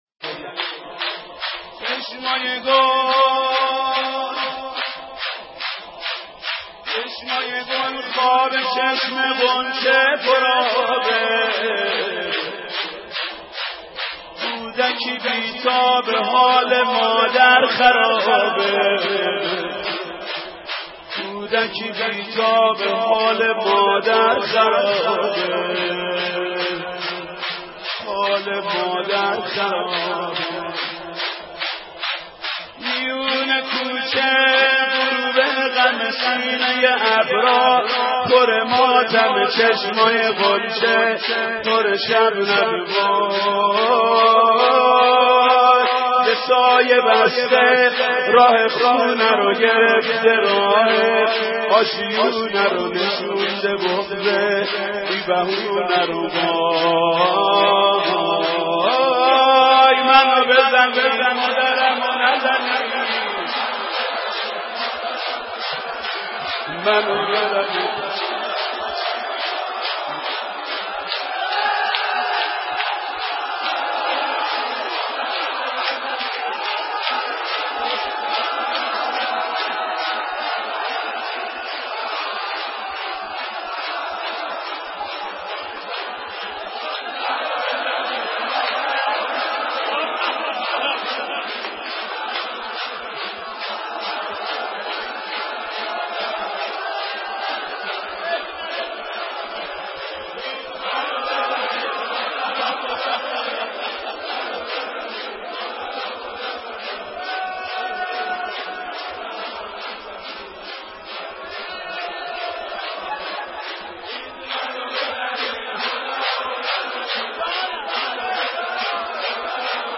دانلود مداحی حال مادر خرابه - دانلود ریمیکس و آهنگ جدید
نوحه خوانی محمود کریمی در ماتم شهادت حضرت زهرا(س) (8:18)